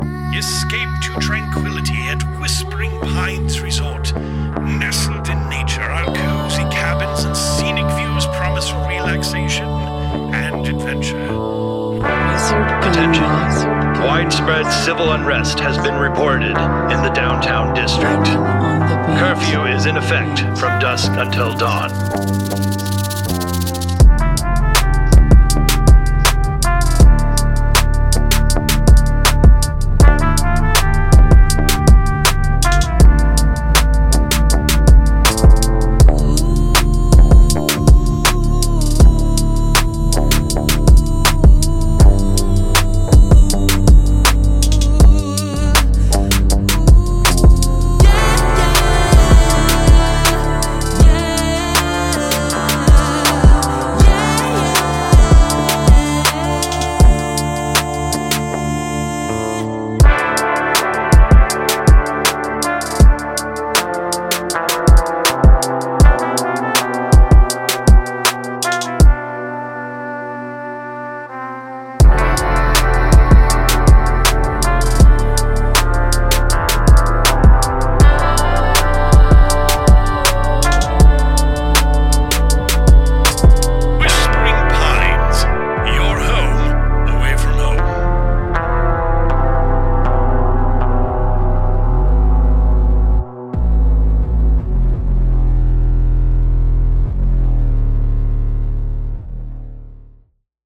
What would a post apocalyptic ghost town sound like in the middle of Texas?